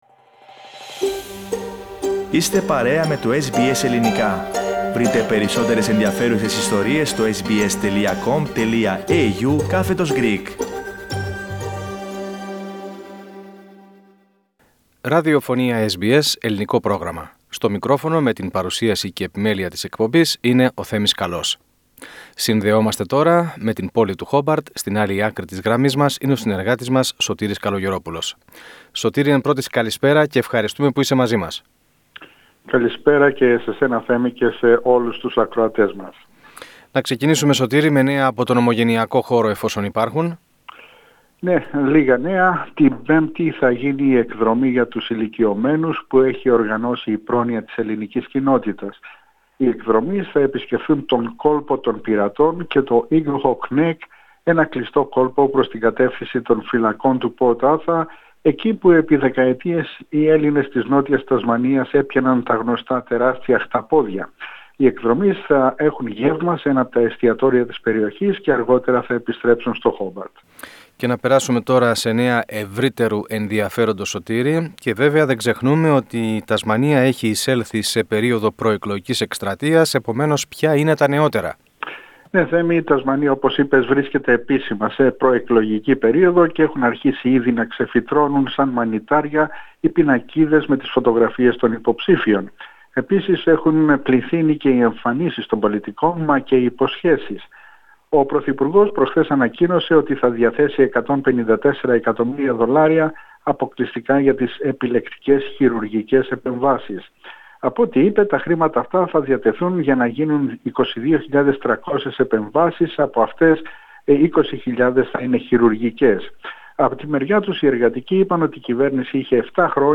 Πατήστε PLAY πάνω στην εικόνα για να ακούσετε την ανταπόκριση μας από την Τασμανία,